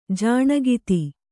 ♪ jāṇagiti